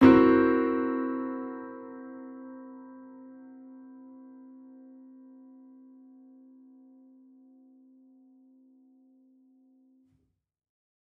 Index of /musicradar/gangster-sting-samples/Chord Hits/Piano
GS_PiChrd-C7b5.wav